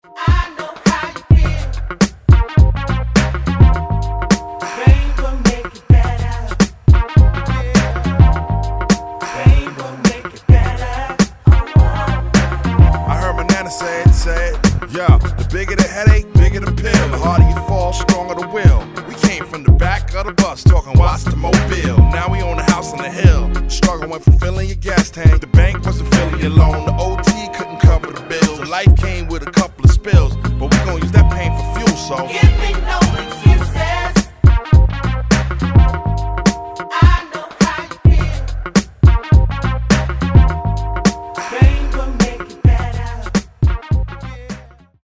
Genre : Hip-hop/Rap